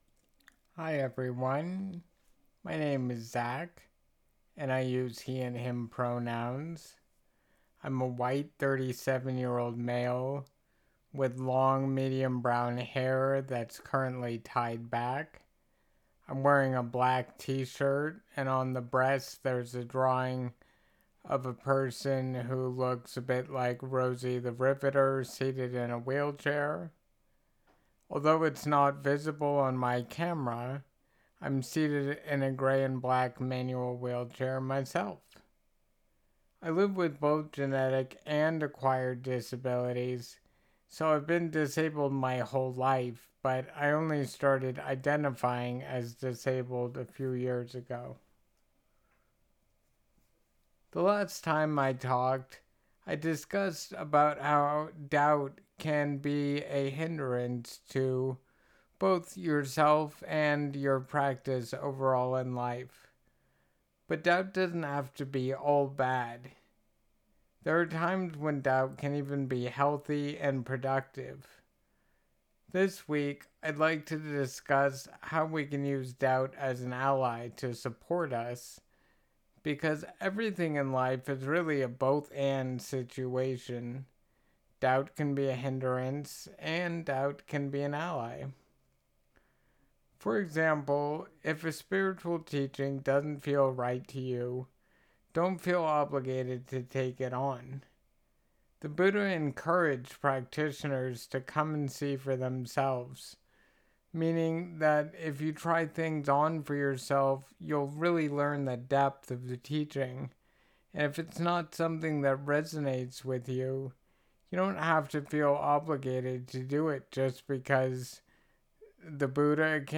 Please Note: The following recording was from a script of a talk given at EBEM, but was recorded at another time to preserve the confidentality of participants.